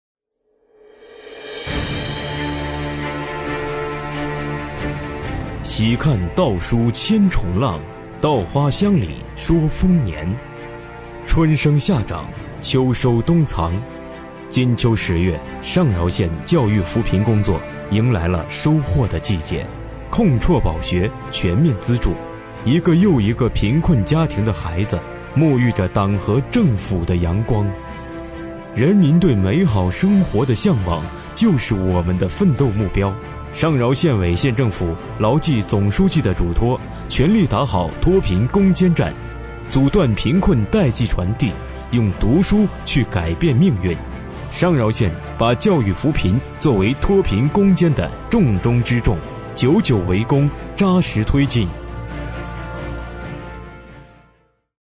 502男声专题大气 501男声宣传大气防控 276男声【专题解说】大气厚重
276男声【专题解说】大气厚重.mp3